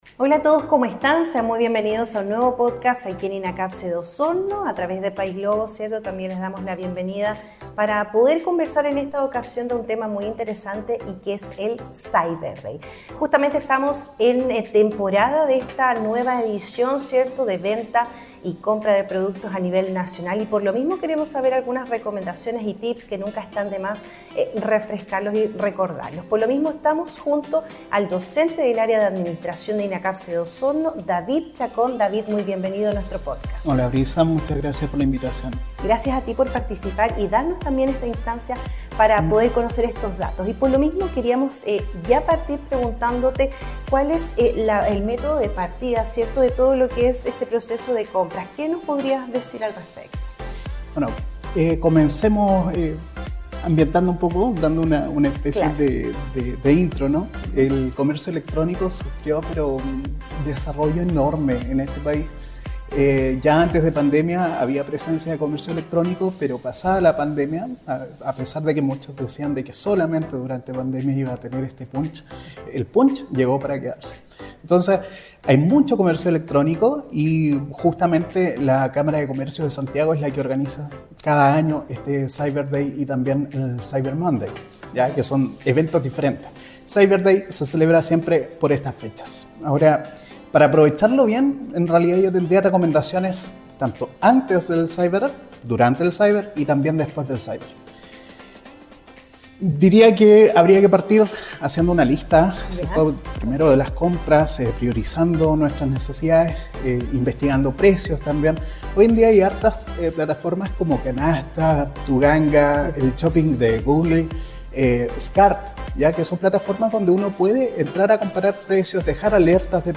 abordan esta entretenida conversación que de seguro te servirá.